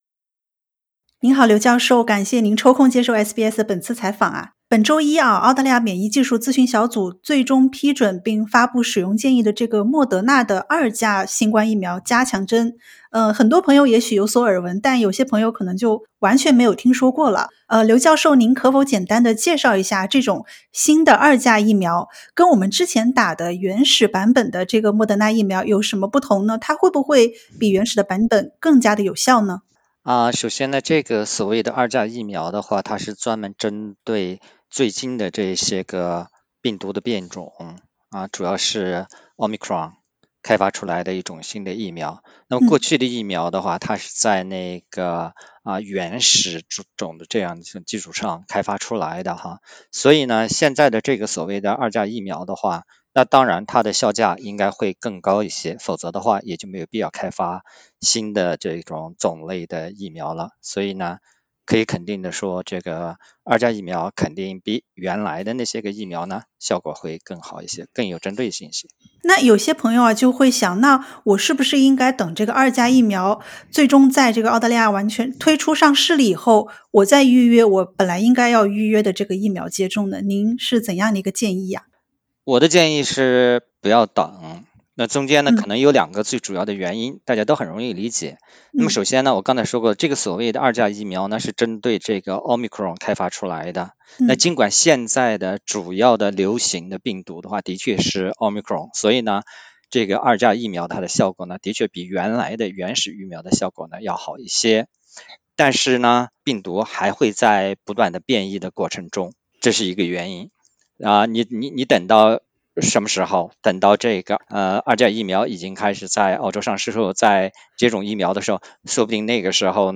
二价疫苗是否更有效？它真的是“疫苗中的战斗苗”吗？公共卫生专家为你解答。